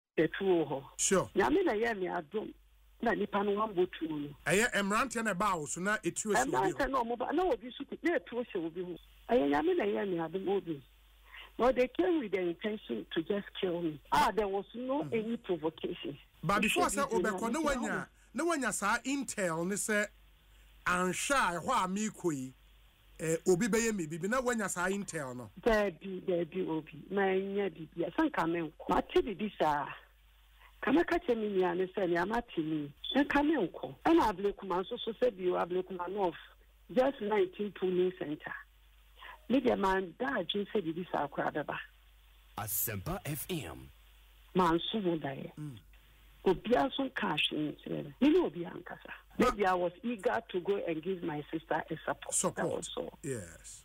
Speaking on Asempa FM’s Ekosii Sen show, Madam Koomson said she only went to the polling centre to support the New Patriotic Party (NPP) candidate, Nana Akua Afriyie, and had no idea such violence would erupt.